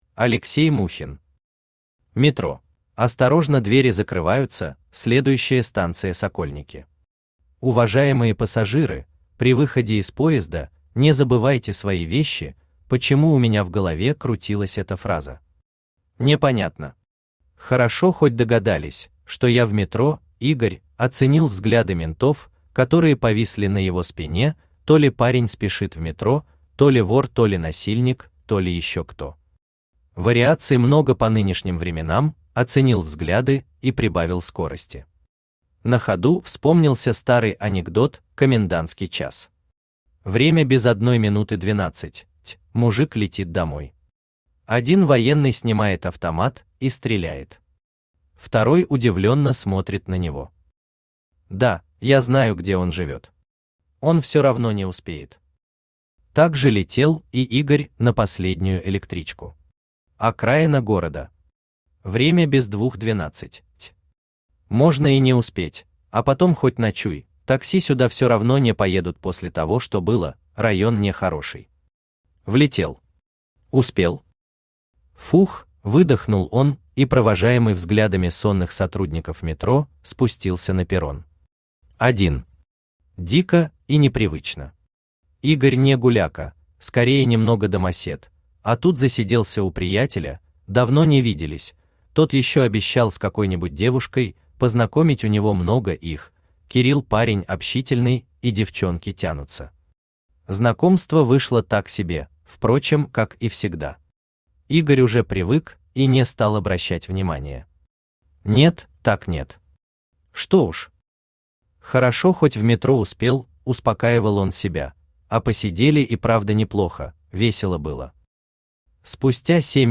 Аудиокнига Метро | Библиотека аудиокниг
Aудиокнига Метро Автор Алексей Аркадьевич Мухин Читает аудиокнигу Авточтец ЛитРес.